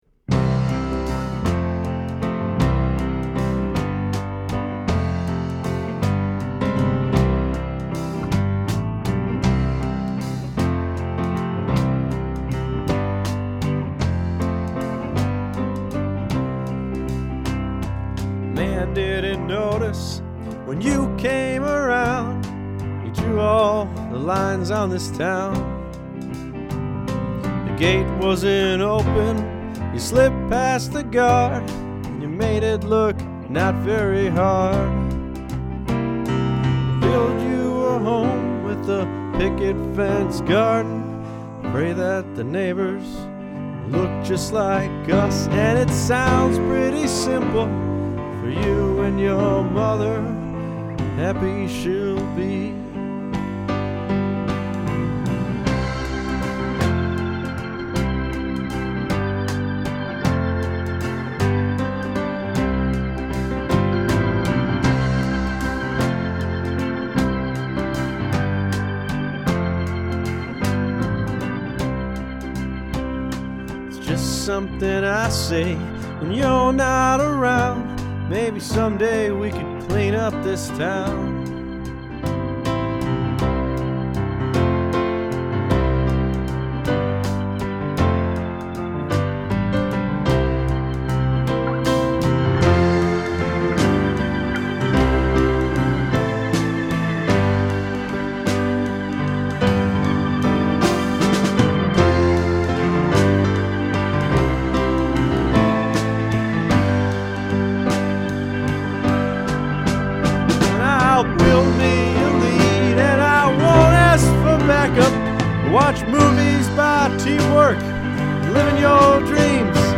guitar
drums and bass
sang oohs
piano and organ